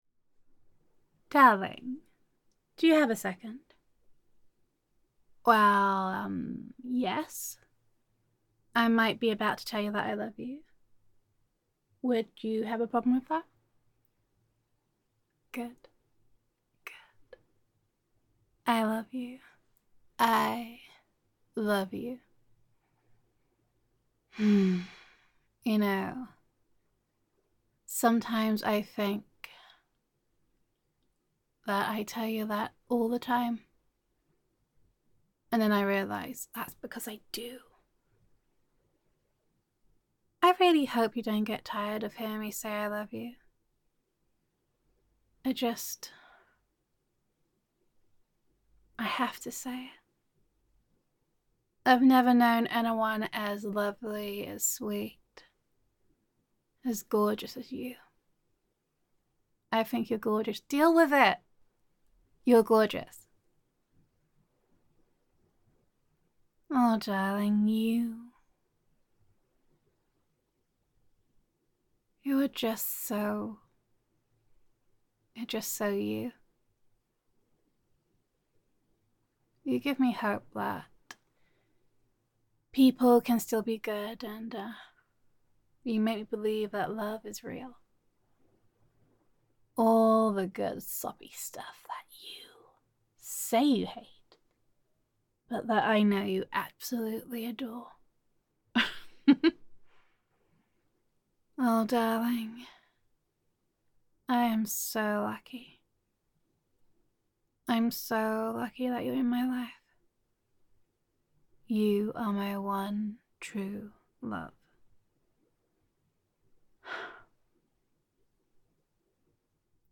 [F4A] Every day I Love You More [Loving][Appreciation][Adoration][Girlfriend Roleplay][Gender Neutral][Sometimes Your Girlfriend Just Needs to Say That She Loves You]